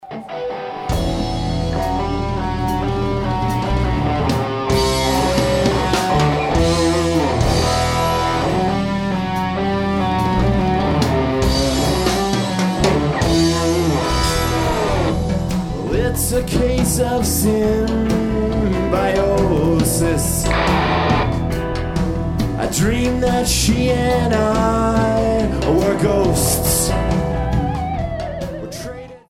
at Ultrasound Showbar in Toronto, Canada